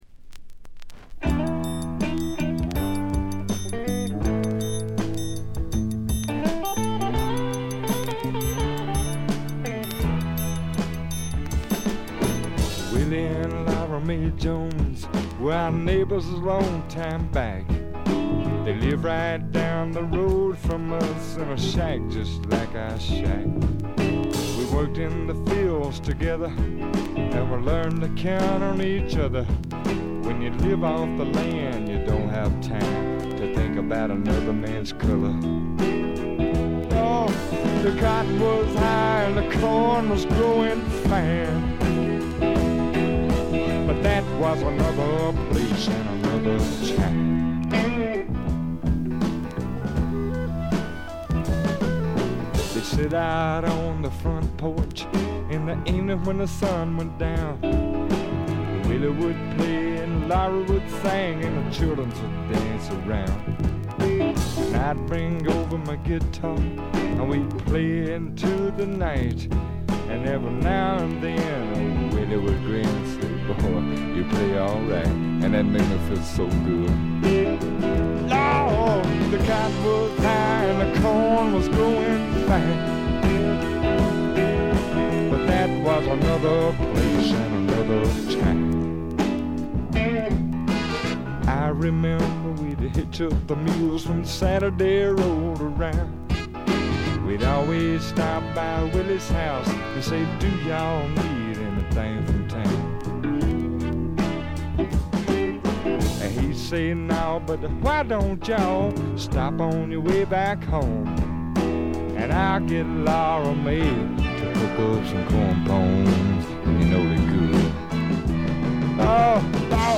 見た目よりよくなくて、バックグラウンドノイズ、チリプチ多め大きめ。
試聴曲は現品からの取り込み音源です。